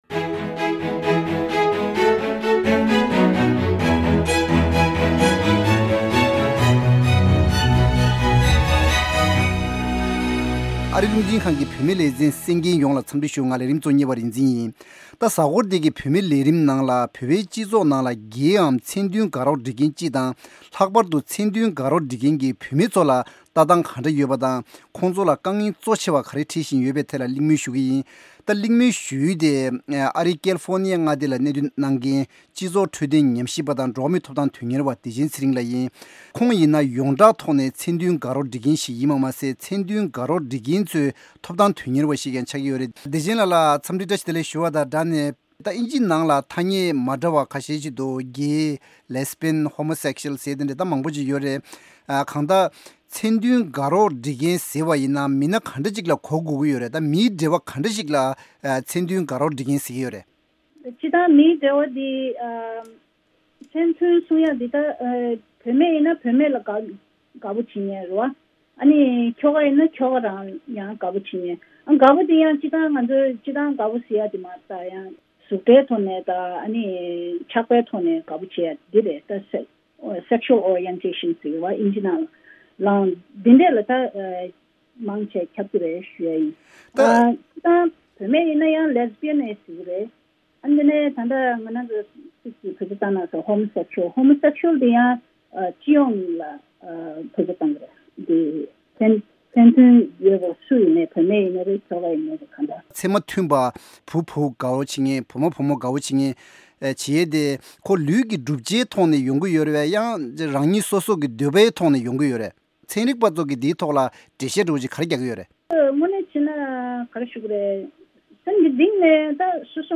བོད་པའི་སྤྱི་ཚོགས་ནང་མཚན་མཐུན་དགའ་རོགས་སྒྲིག་མཁན་སྤྱི་དང་ལྷག་པར་དུ་མཚན་མཐུན་དགའ་རོགས་སྒྲིག་མཁན་གྱི་བོད་པའི་བུད་མེད་ཚོར་ལྟ་སྟངས་ག་འདྲ་ཡོད་པ་དང། ཁོང་ཚོ་ལ་དཀའ་ངལ་གཙོ་ཆེ་བ་ག་རེ་འཕྲད་བཞིན་ཡོད་པའི་ཐད་གླེང་མོལ་ཞུས་ཡོད།